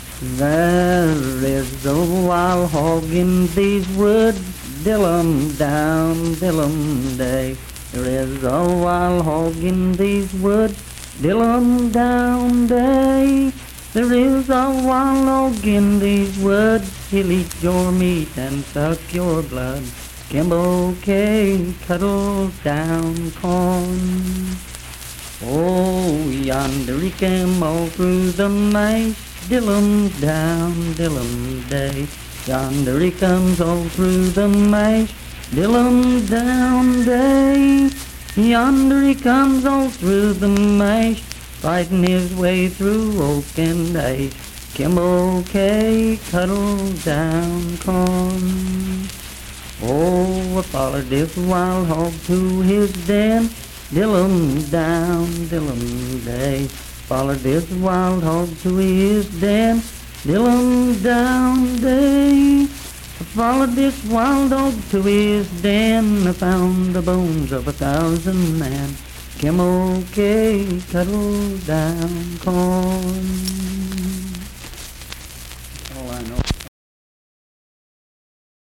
Unaccompanied vocal performance
Verse-refrain 3 (7w/R).
Voice (sung)